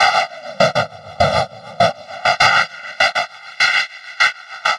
Index of /90_sSampleCDs/Transmission-X/Percussive Loops
tx_perc_100_pseudotrain.wav